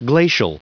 Prononciation du mot glacial en anglais (fichier audio)